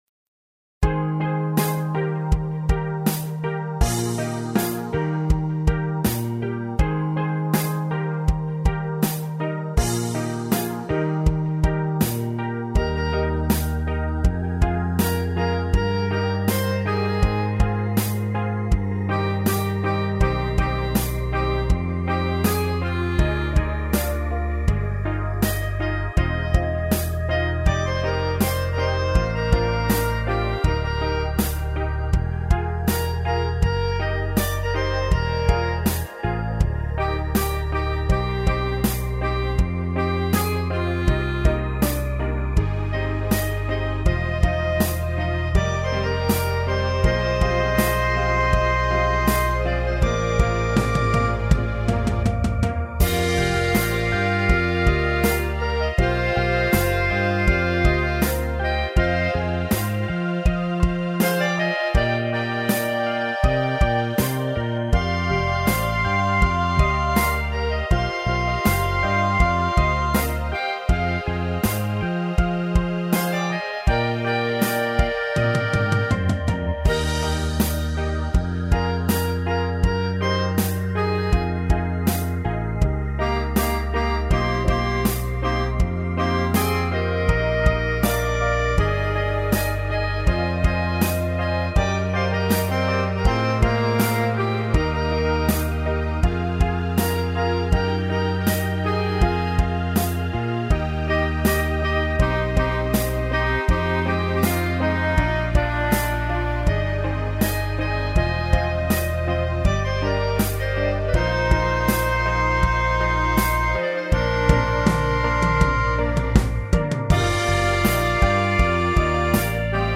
Home > Music > Pop > Bright > Smooth > Medium